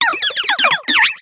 Cri de Candine dans Pokémon Soleil et Lune.